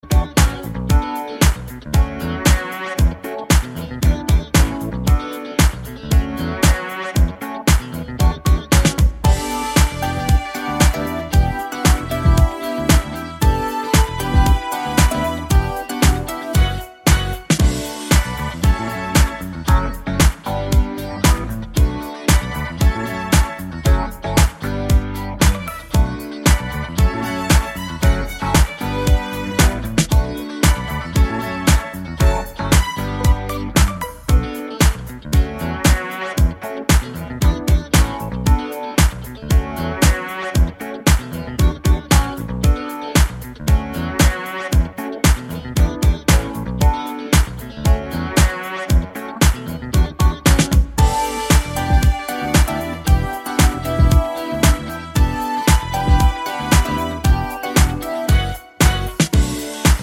no Backing Vocals Disco 3:35 Buy £1.50